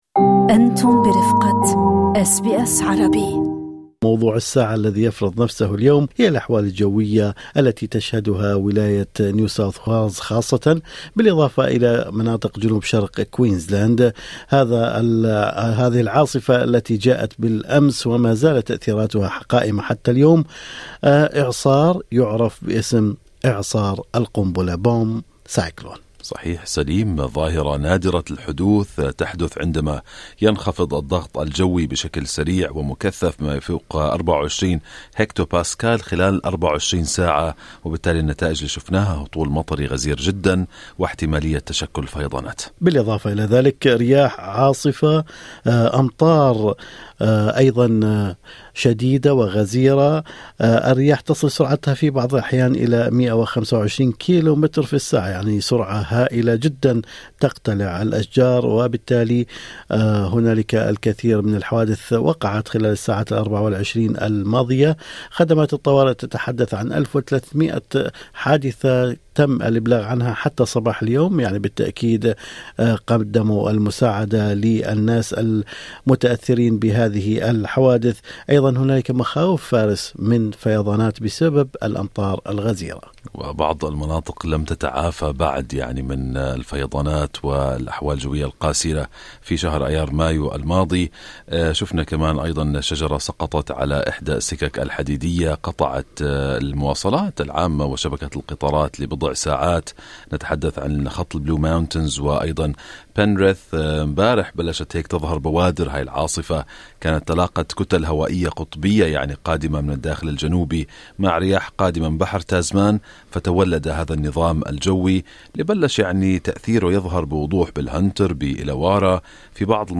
وفي مقابلة